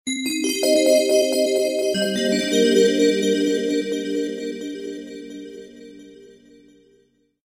bells-effect_24806.mp3